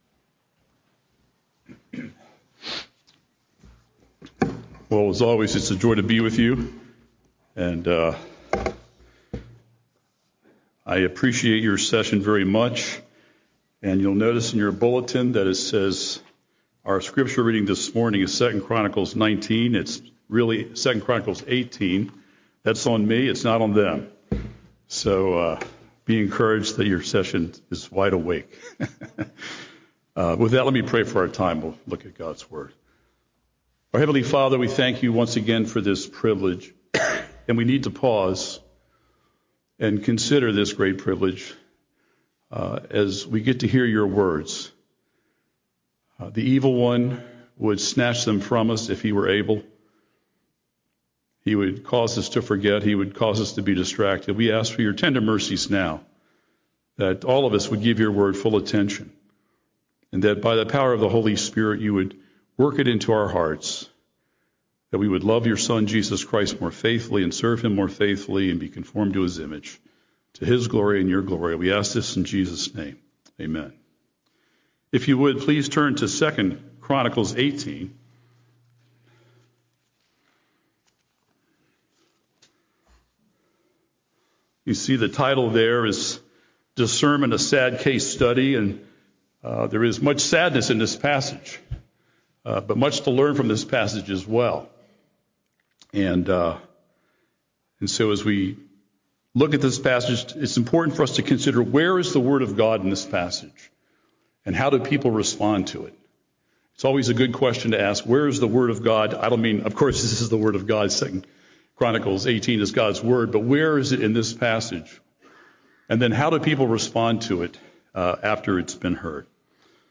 Discernment – A Sad Case Study: Sermon on 2Chronicles 18